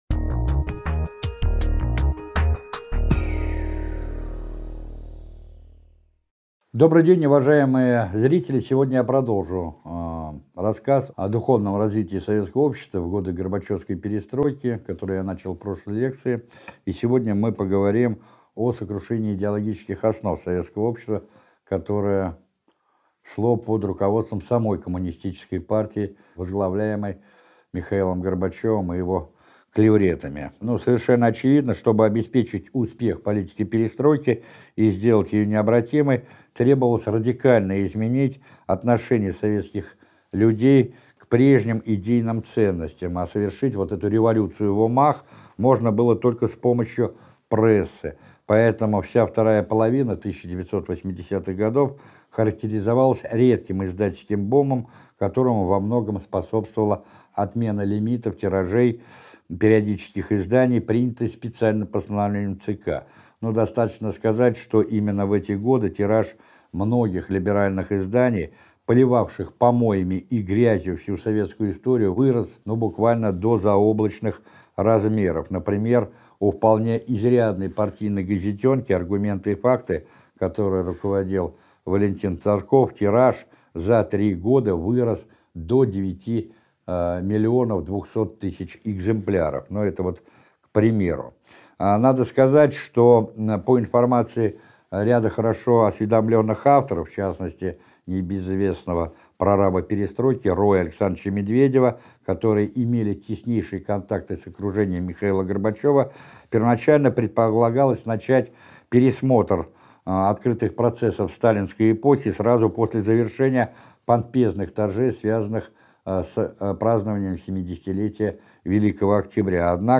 Сто пятьдесят пятая лекция из цикла «История России» посвящена духовной деградации советского общества во второй половине 1980-х гг.